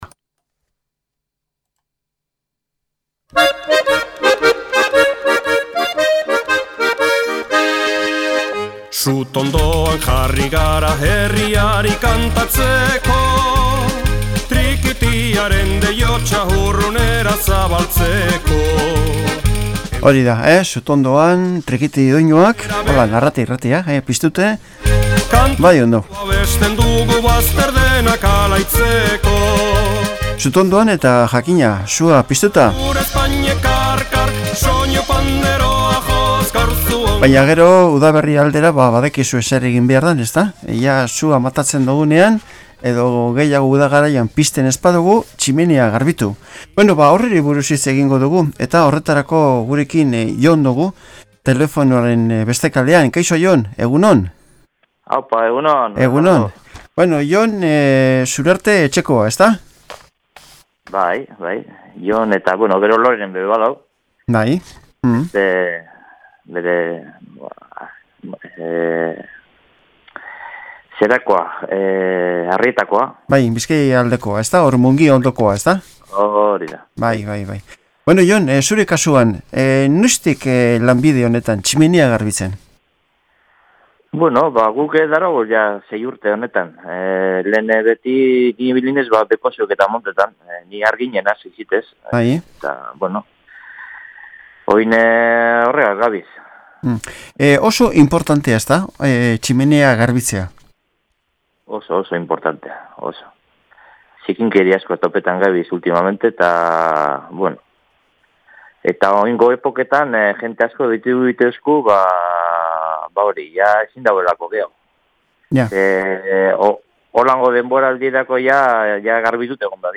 Alkarrizketa